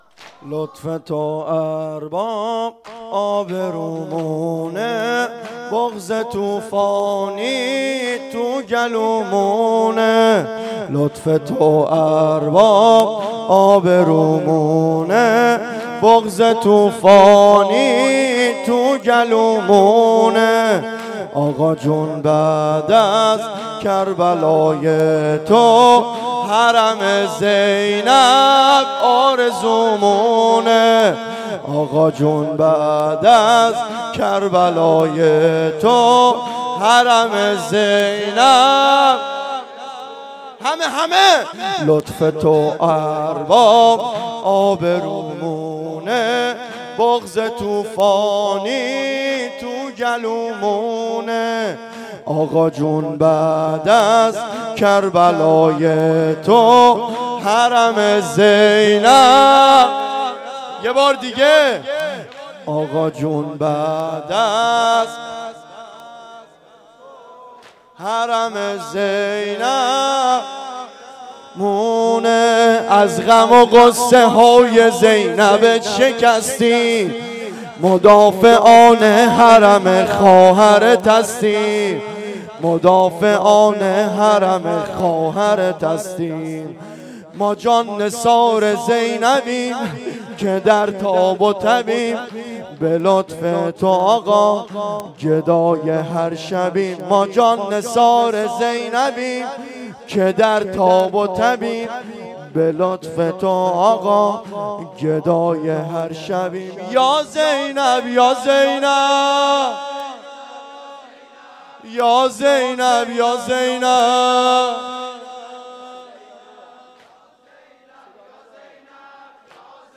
واحد شور1